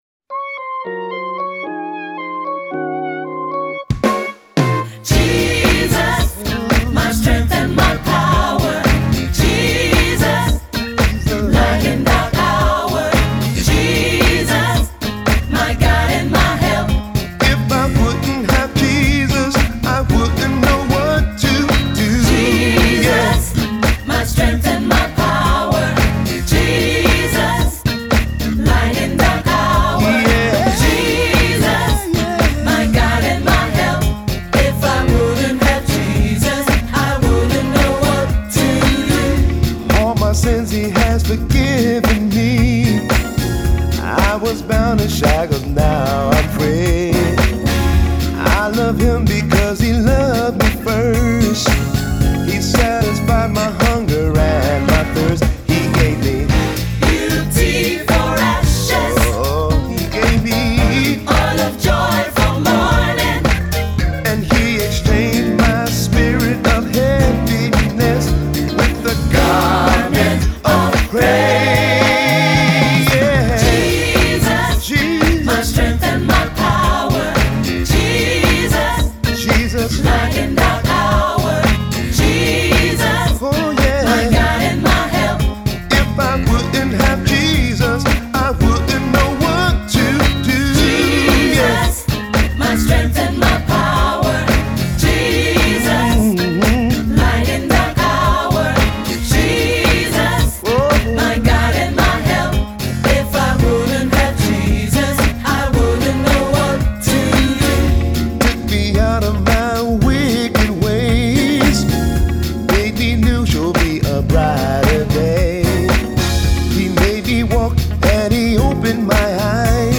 GOSPELNOTEN
• SAB (SSA), Solo + Piano